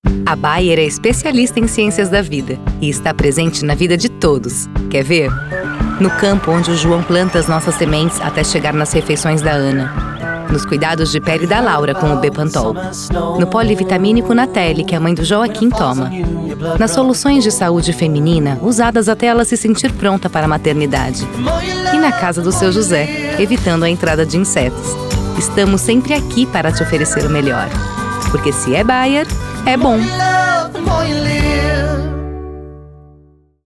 Commercial
With a versatile, mature, and pleasant voice, I am able to adapt my narration style to the needs of the project, creating a unique and engaging experience for the listener.
With an excellent acoustic treatment system that offers excellent quality.